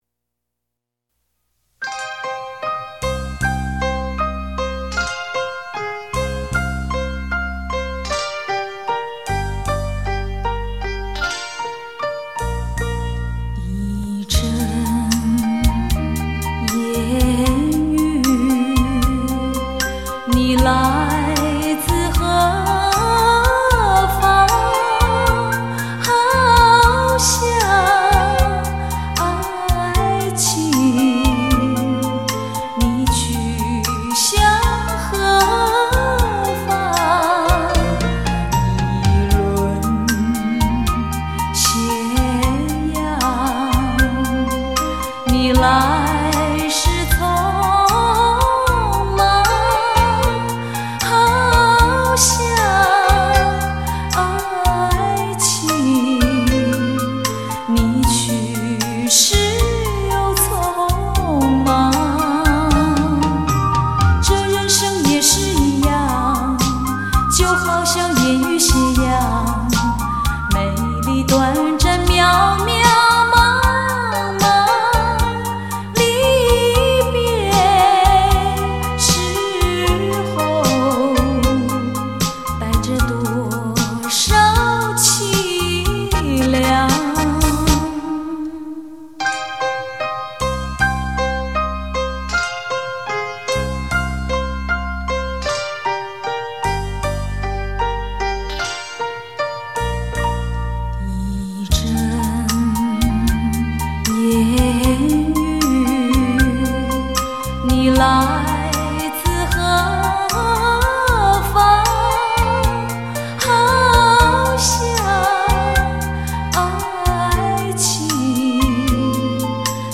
勃露斯
REM GENRE Dance